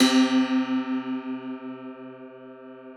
53q-pno06-C1.wav